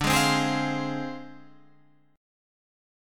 Dm7b5 chord